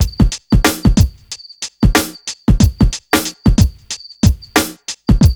1HF92BEAT1-R.wav